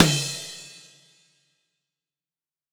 normal-hitfinish.wav